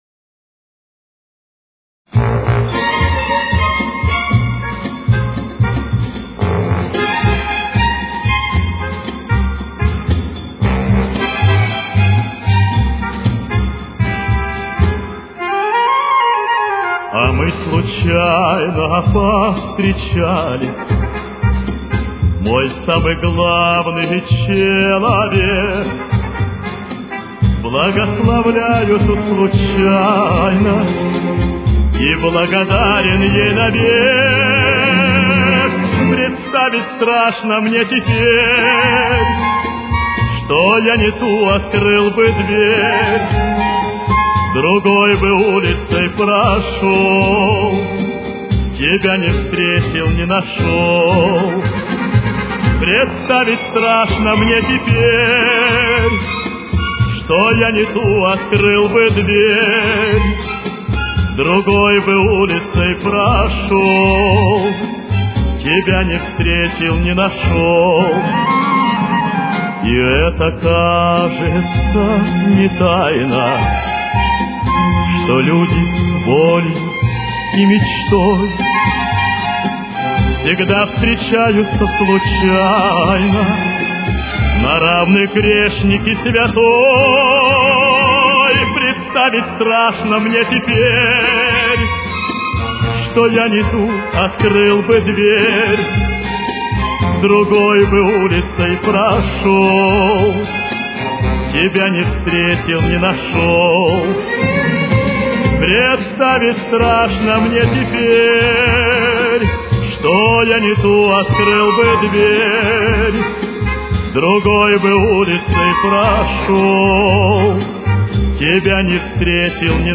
Темп: 116.